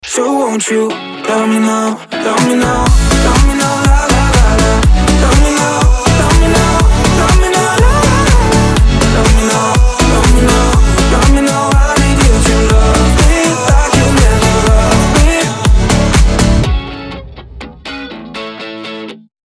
Драйвовые